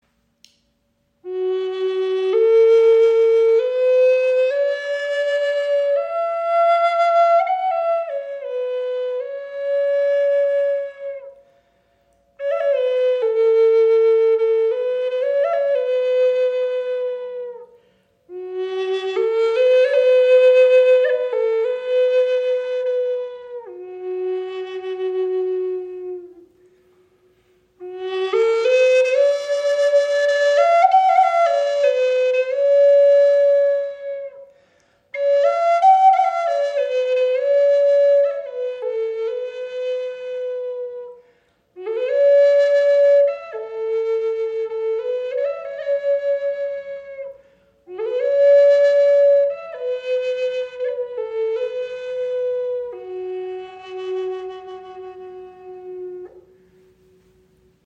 Gebetsflöte in G - 432 Hz im Raven-Spirit WebShop • Raven Spirit
Klangbeispiel
Diese wundervolle Gebetsflöte ist auf G Moll in 432 Hz gestimmt und erzeugt einen warmen, tragenden Klang.